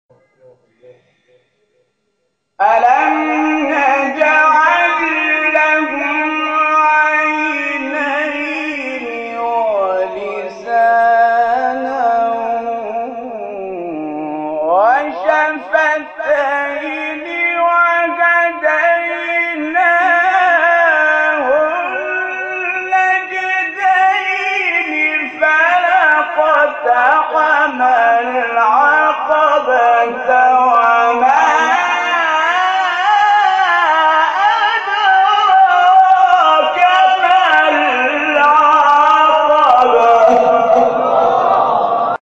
گروه شبکه اجتماعی: جدیدترین مقاطع صوتی از تلاوت قاریان بنام و ممتاز کشور را که به تازگی در شبکه‌های اجتماعی منتشر شده است، می‌شنوید.